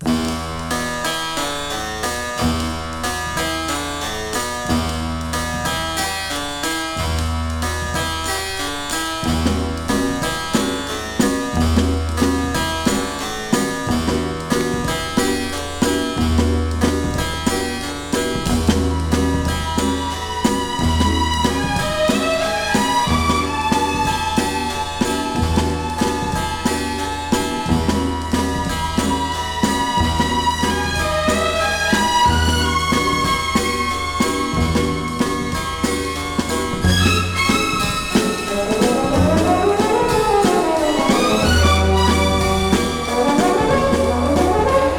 趣向の凝ったアレンジと様々な音で彩られ、次から次への魅了される最高のポピュラー音楽集です。
Jazz, Easy Listening　USA　12inchレコード　33rpm　Stereo